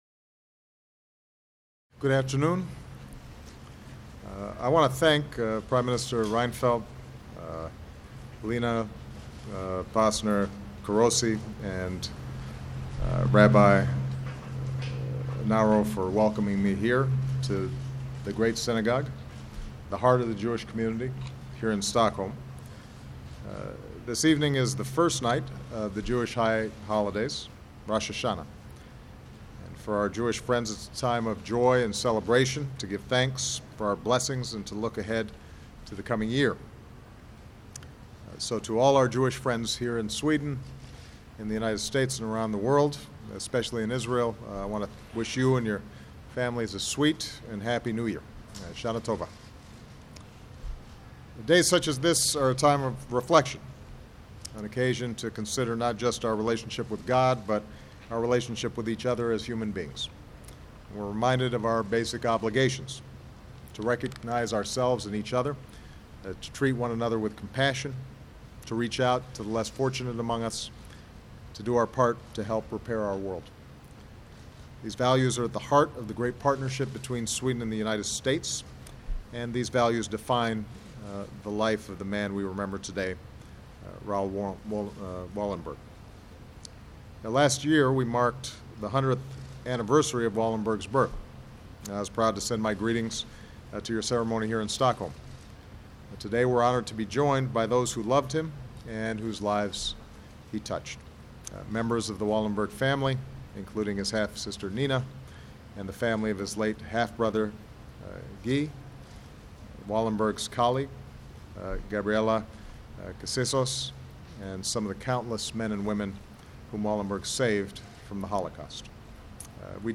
U.S. President Barack Obama speaks in an event in Stockholm honoring Raoul Wallenberg
President Obama participates in an event at the Great Synagogue in Stockholm honoring Raoul Wallenberg, a Swedish diplomat and honorary U.S. citizen who worked courageously to save Jewish lives while serving as Sweden's special envoy in Budapest during World War II. Obama says Wallenberg serves as an example to us all not simply to bear witness, but to act.